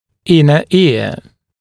[‘ɪnə ɪə][‘инэ иа]внутреннее ухо